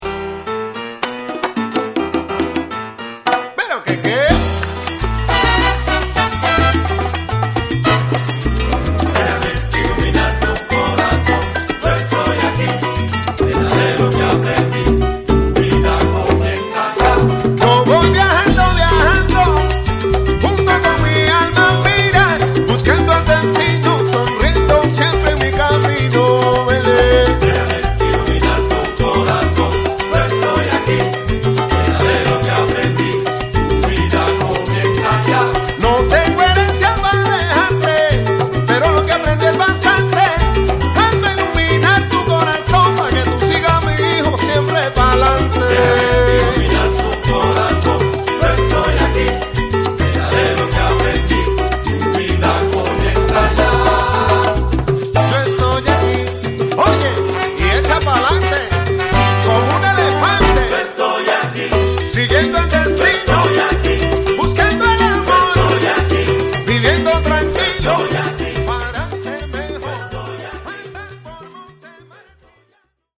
orchestra di salsa, merengue, boleros, cumbia
voce e percussioni
piano
basso e coro
batteria e timbales
congas
tromba
trombone
sax tenore